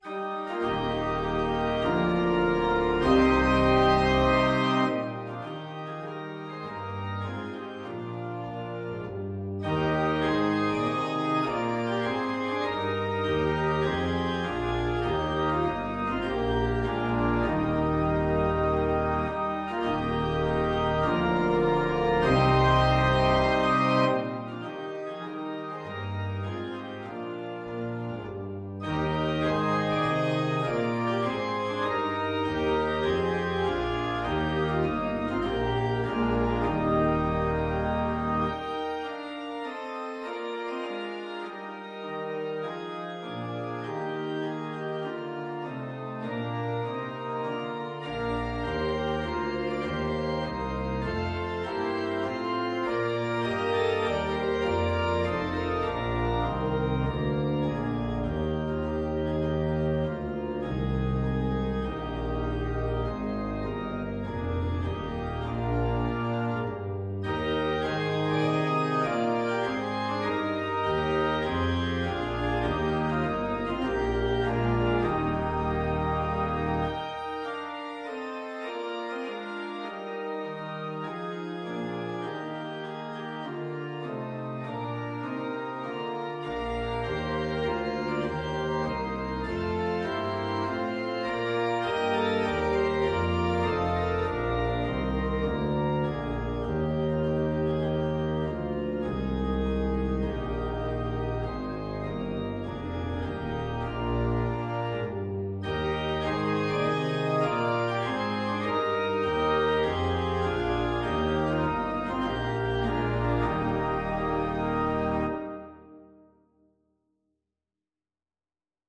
Free Sheet music for Organ
4/4 (View more 4/4 Music)
Eb major (Sounding Pitch) (View more Eb major Music for Organ )
Classical (View more Classical Organ Music)